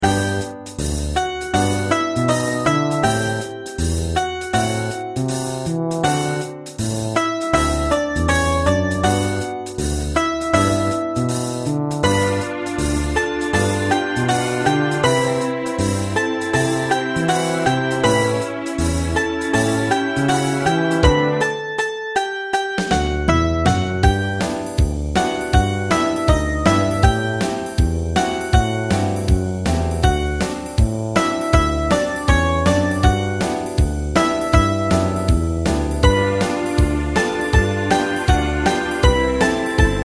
カテゴリー: ユニゾン（一斉奏） .
ポピュラー